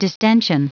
Prononciation du mot distension en anglais (fichier audio)
Prononciation du mot : distension